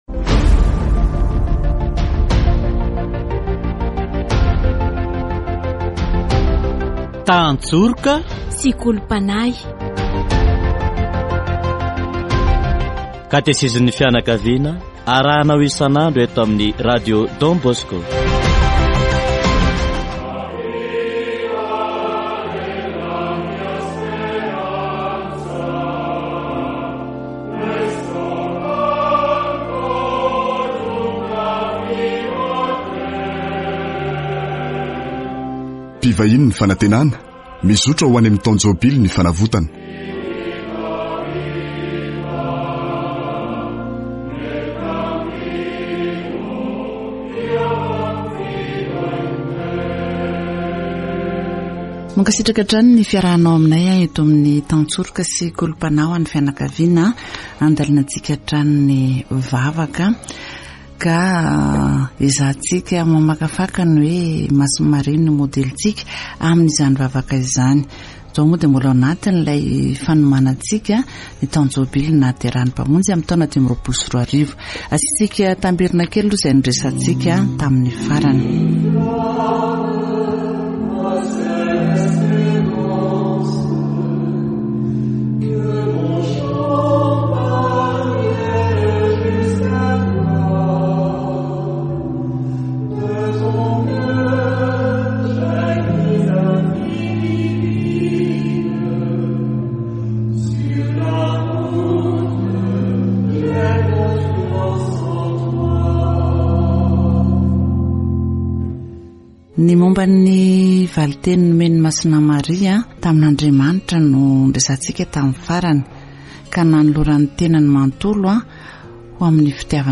Catechesis on Mary